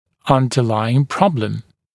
[ˌʌndə’laɪŋ ‘prɔbləm][ˌандэ’лаин ‘проблэм]лежащая в основе проблема